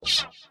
retweet_send.ogg